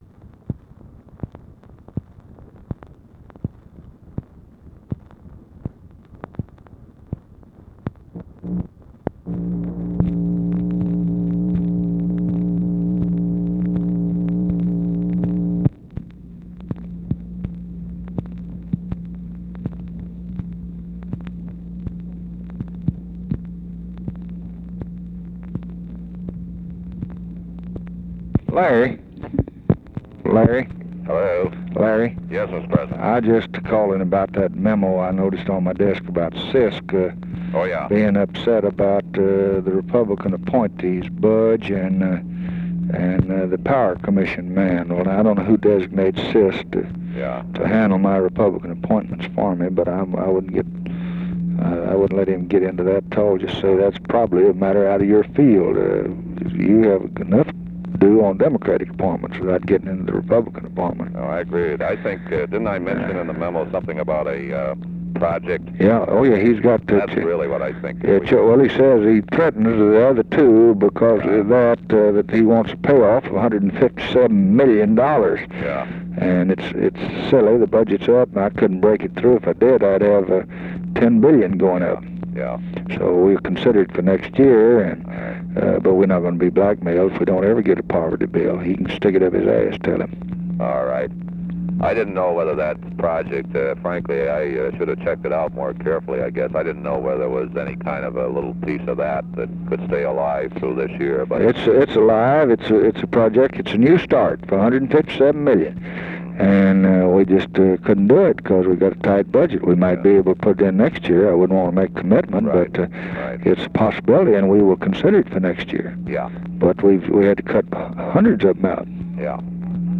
Conversation with LARRY O'BRIEN, June 15, 1964
Secret White House Tapes